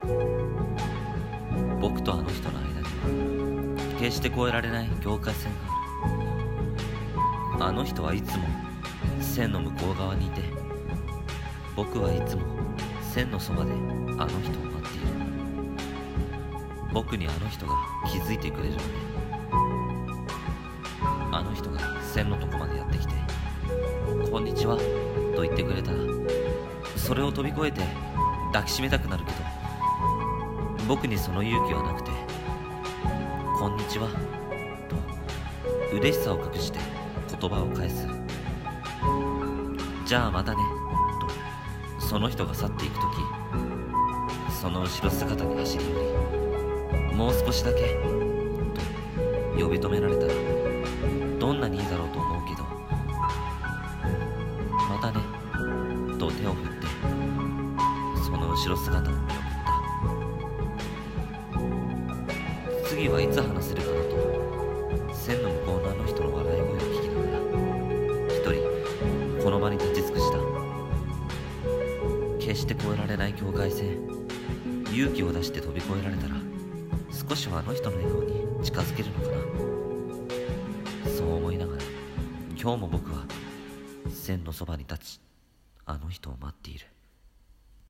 【声劇/朗読台本】コミュ障